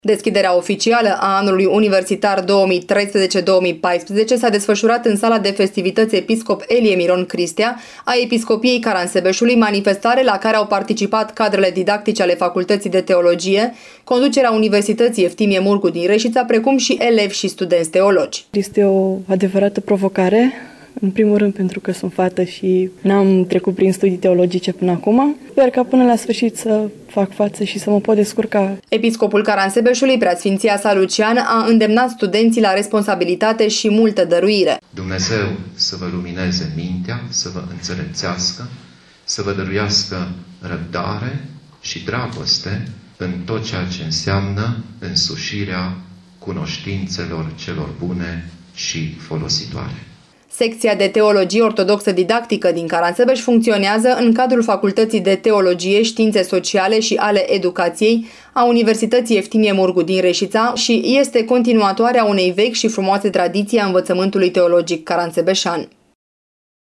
Episcopul Caransebeşului PS Lucian a îndemnat studenţii şi masteranzii la responsabilitate şi multă dăruire: